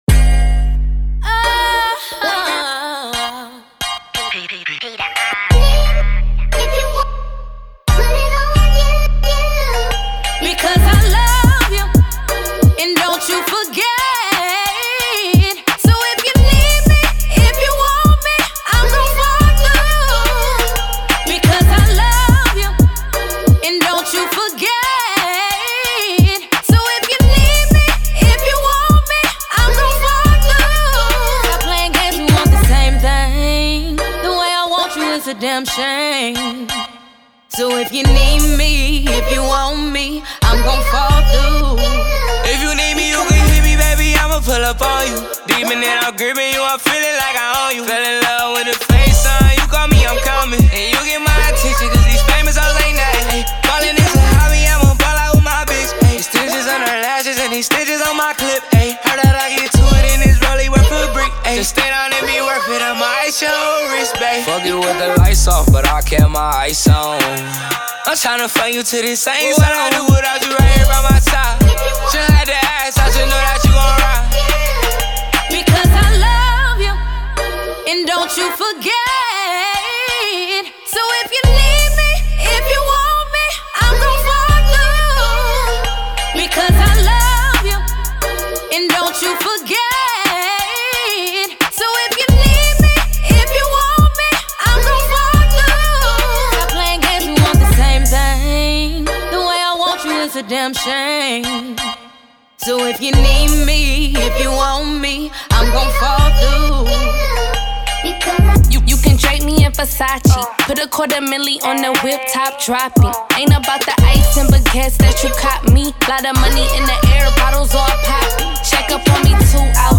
Hiphop
floats through with intoxicating flow.